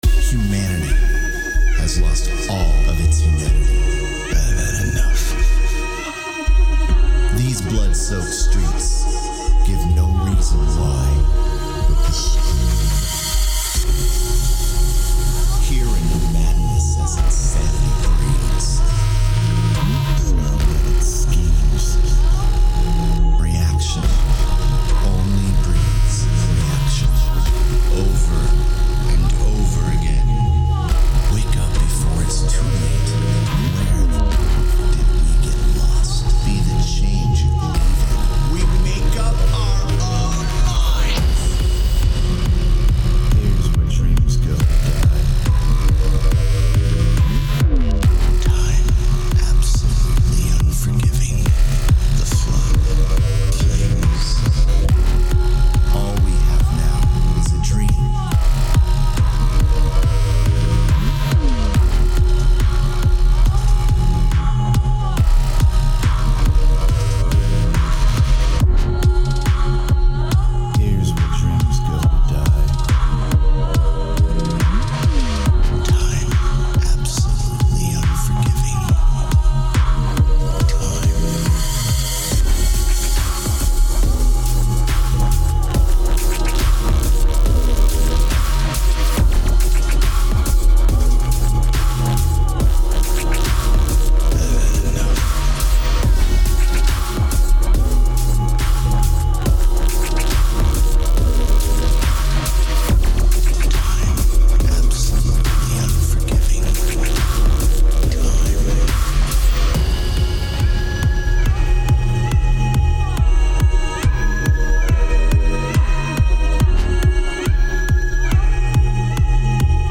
the Clean Version is a bassy deep dark dubstep track
Tempo 140BPM (Allegro)
Genre Deep Dark Dubstep
Type Vocal Music
Mood Conflicting [Aggressive/energetic/sorrow]